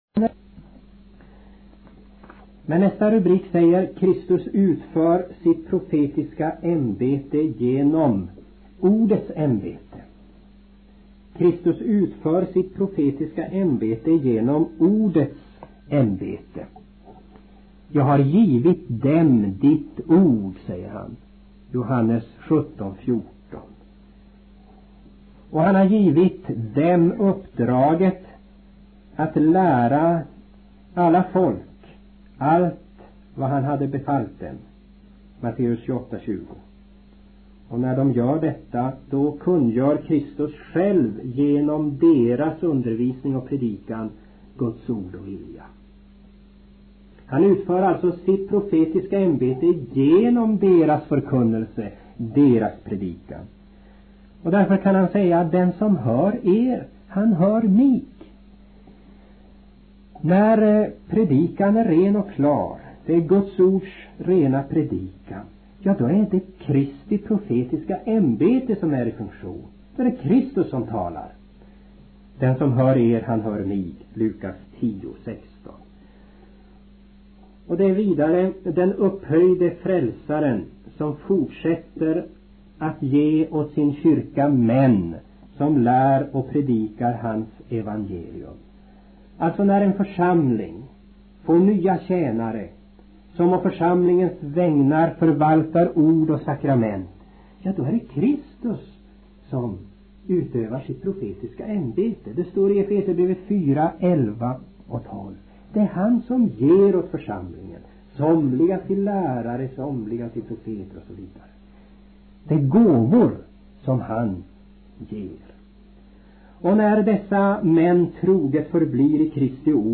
Föredragen hölls under åren 1979–81 i Uppsala.